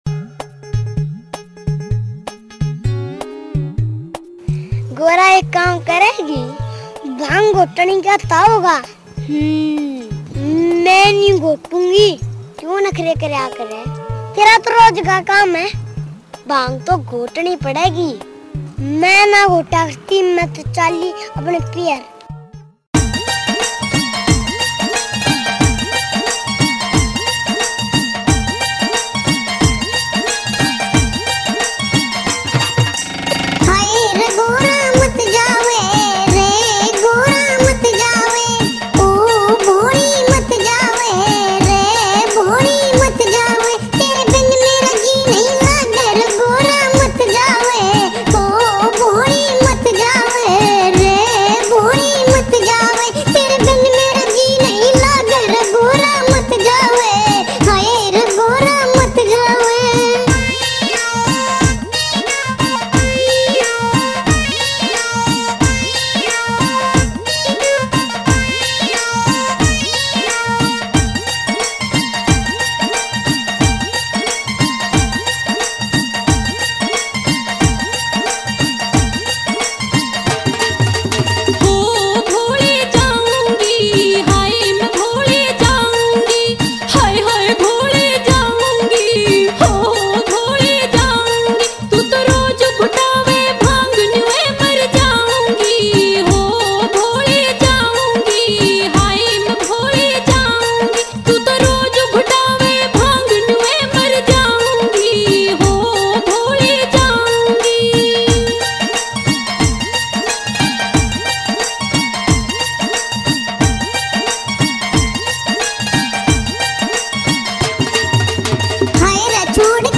Shiv Bhajan